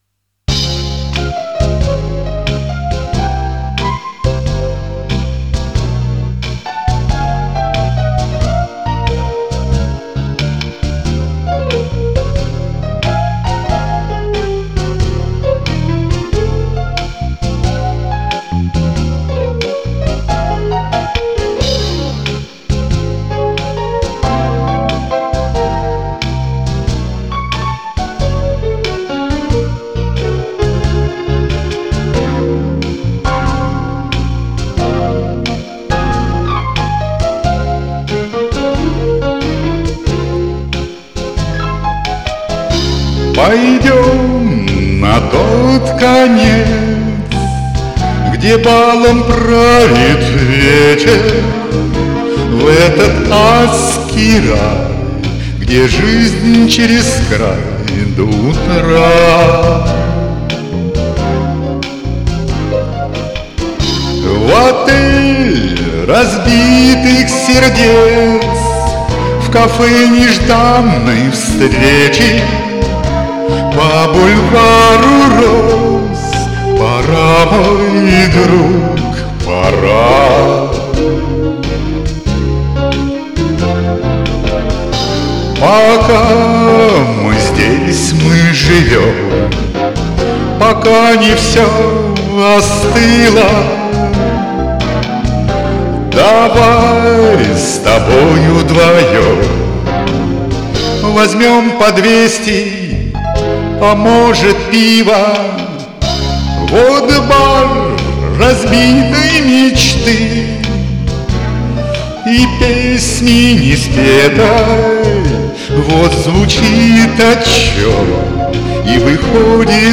Для разнообразия добавил партию трубы на своём синтезаторе.